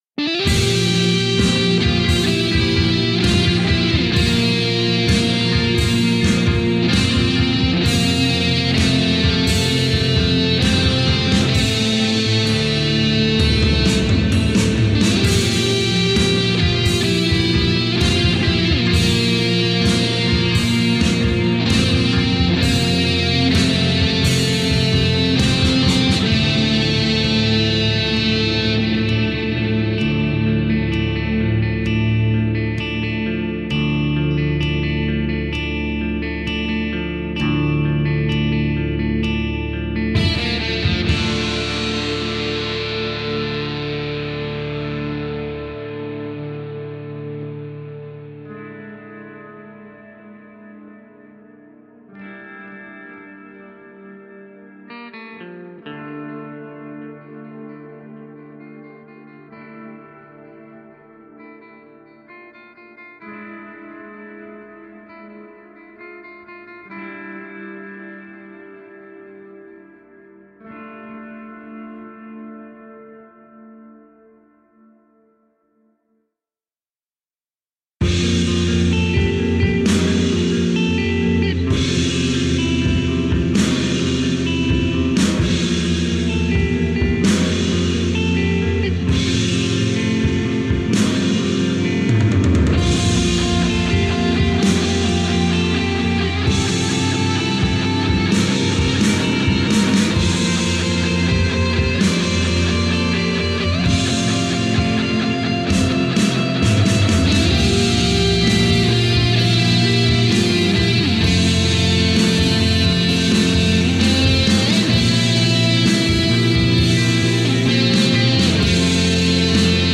Ambient post-rock from the cold heart of siberia.
Tagged as: Alt Rock, Experimental, Prog Rock